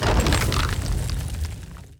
Rock Wall 2.wav